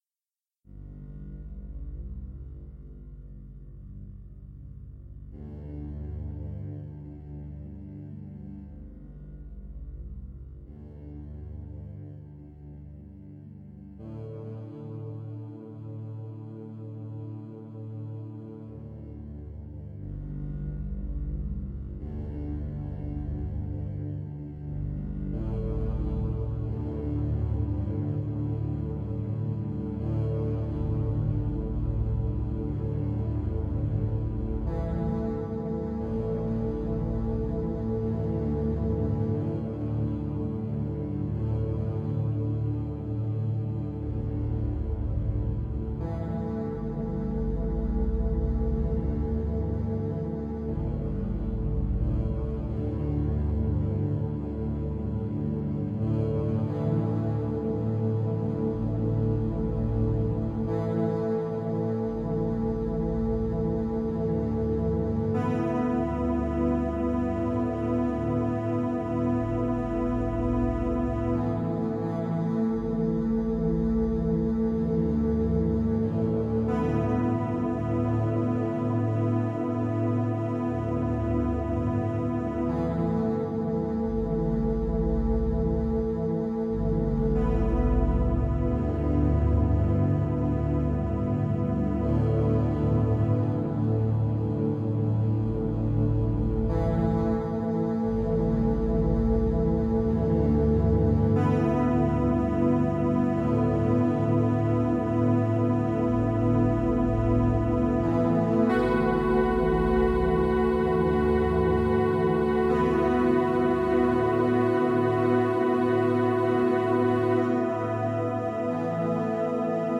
computer music      2016      duration: 4:38      San Marcos, Texas